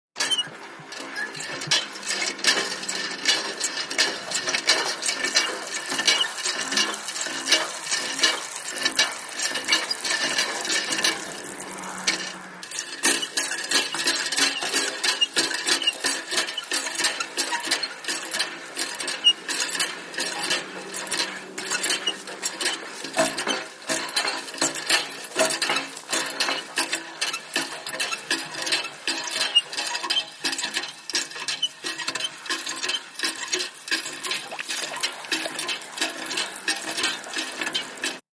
MACHINES - CONSTRUCTION HAND MANUAL WATER PUMP: EXT: Pumping with water running into metal container, squeaky lever & mechanism.